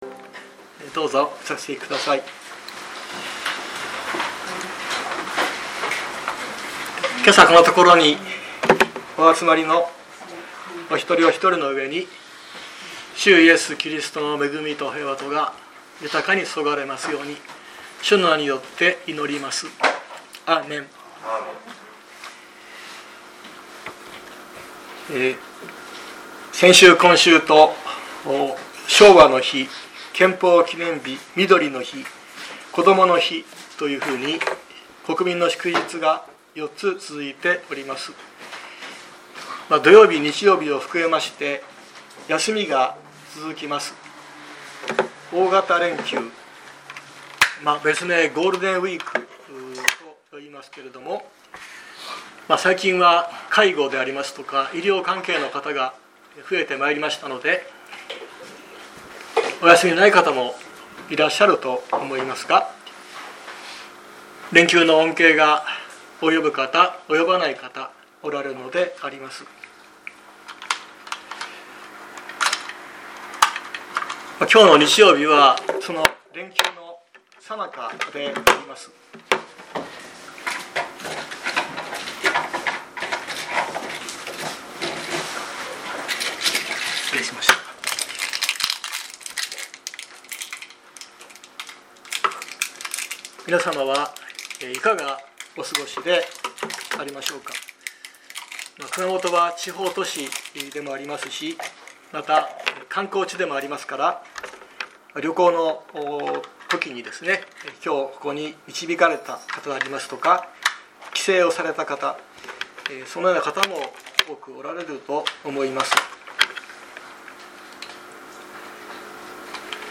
2024年05月05日朝の礼拝「命の書、聖書」熊本教会
熊本教会。説教アーカイブ。
毎週日曜日10時30分より神様に感謝と祈りをささげる礼拝を開いています。